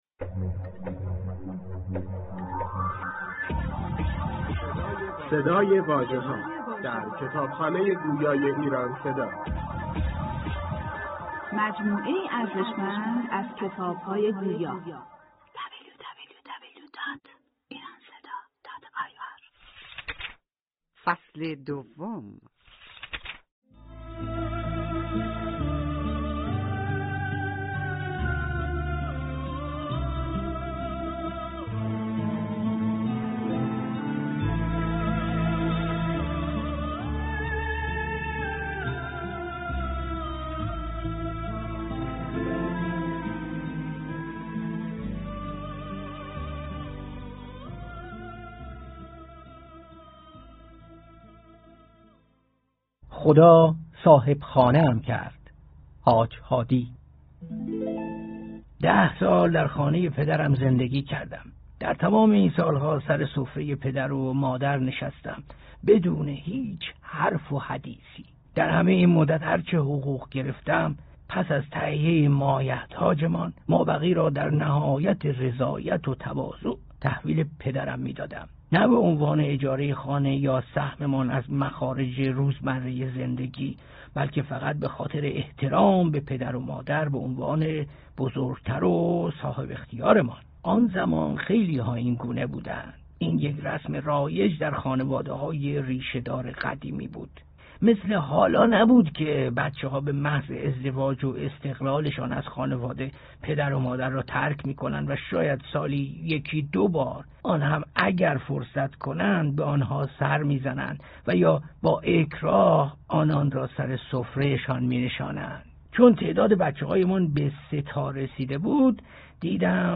كتاب «اكیپ حاج هادی» به کوشش شبکه پایگاه جامع کتاب گویای ایران صدا در قالب صوتی منتشر شده است.